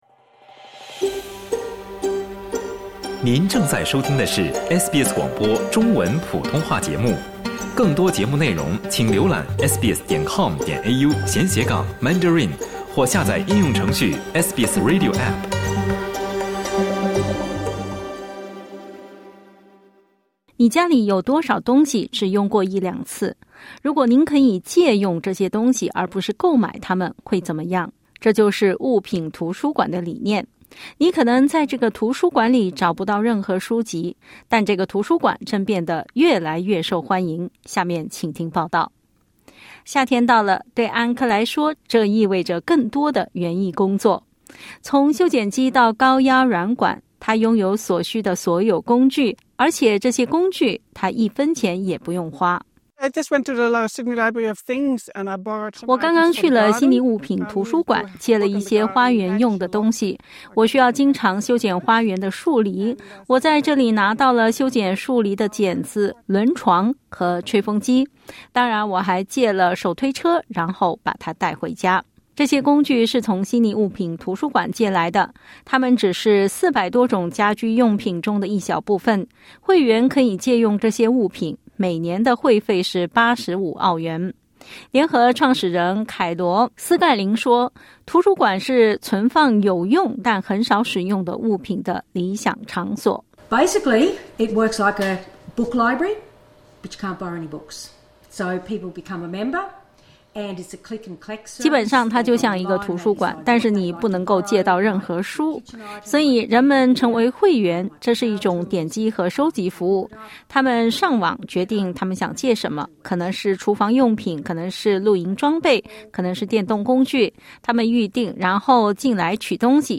这就是物品图书馆（Library of Things）的理念。 （点击音频收听详细报道）